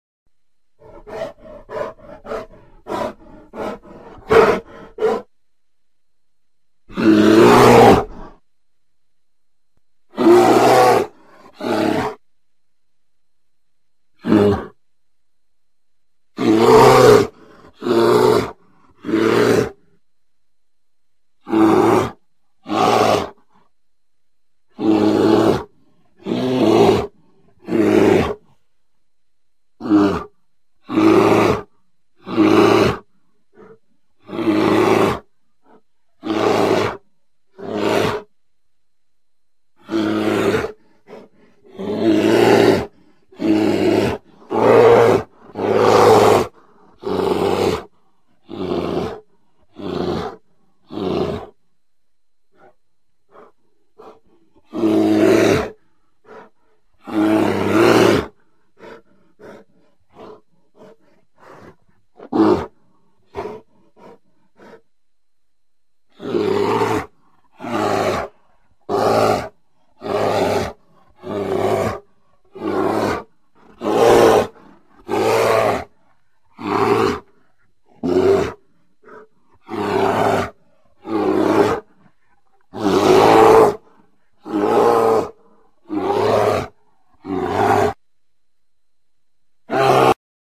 دانلود آهنگ خرس از افکت صوتی انسان و موجودات زنده
دانلود صدای خرس از ساعد نیوز با لینک مستقیم و کیفیت بالا
جلوه های صوتی